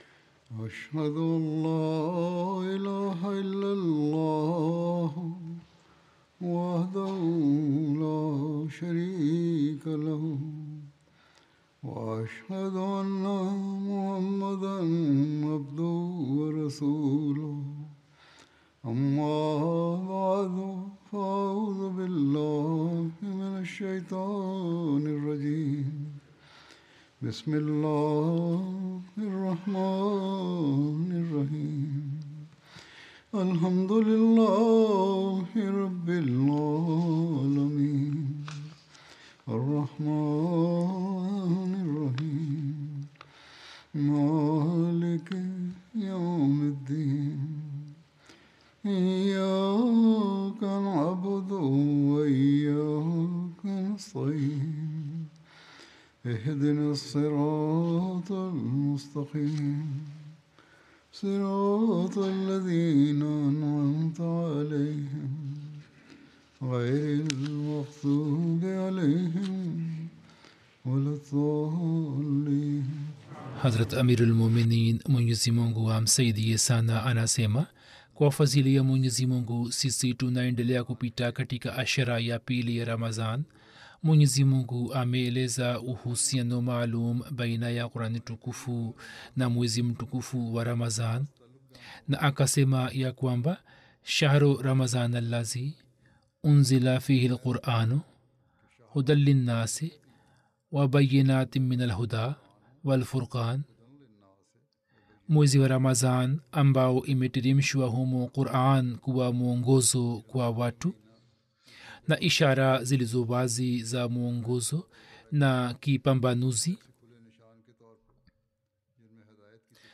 Muhammad (sa): The Great Exemplar Swahili Friday Sermon By Head Of Ahmadiyya Muslim Community podcast